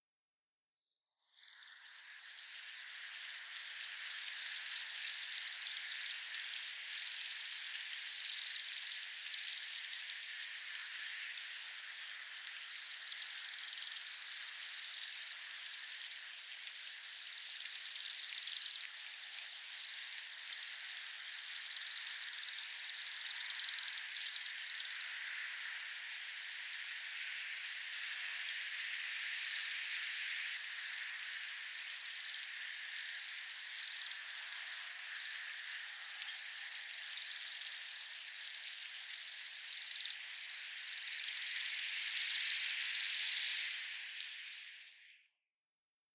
pestvogel
🇬🇧 English: bohemian waxwing
♪ contactroep
pestvogel nov 2016 contactroep.mp3